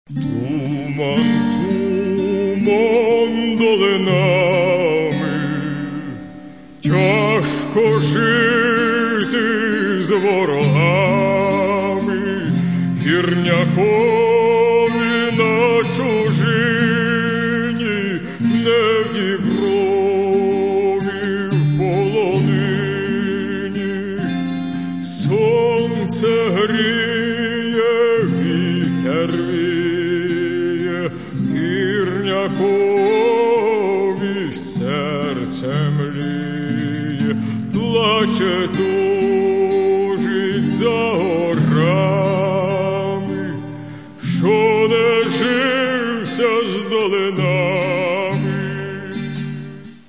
Каталог -> Народная -> Бандура, кобза